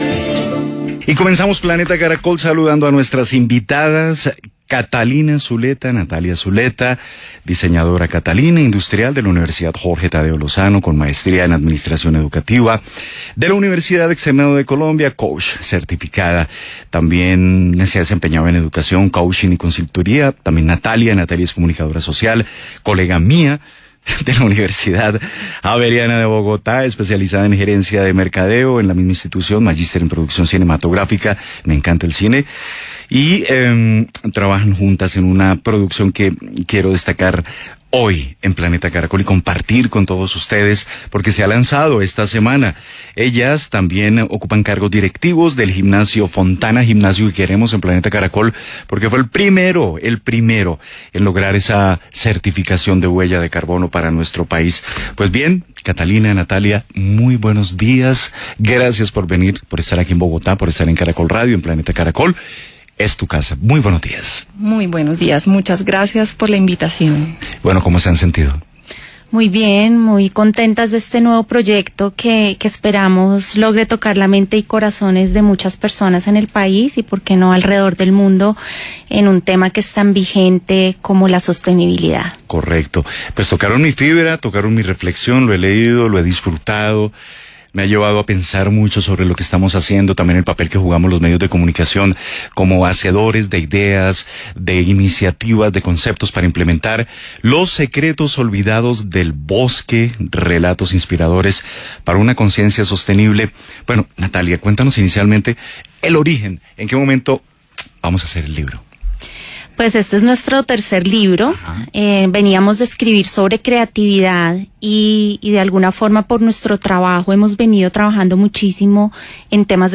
Planeta Caracol de Caracol Radio habló con ellas.